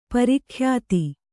♪ pari khyāti